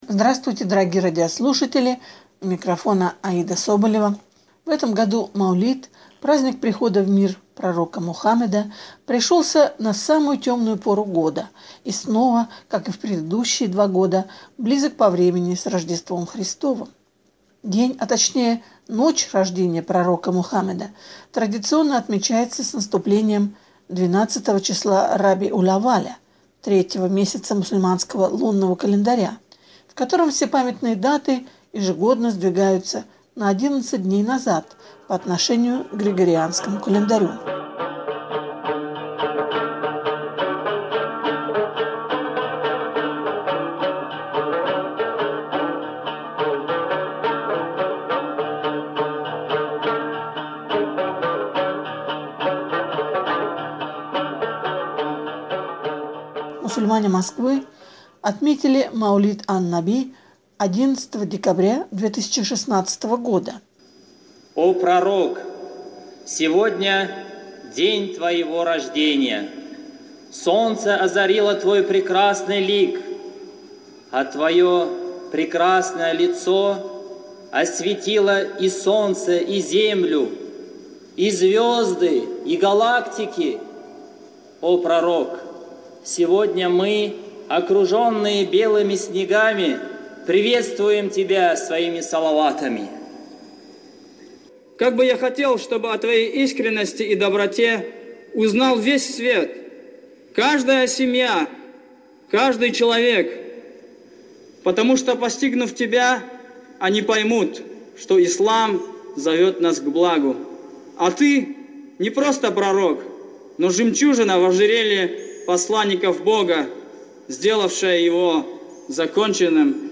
Мусульмане Москвы отметили Маулид ан-Наби 11 декабря 2016 года. (фрагмент праздничной программы в Московской Соборной мечети)
12 Раби уль-Авваля здесь звучали нашиды и салаваты на разных языках и обращенные к Пророку стихи: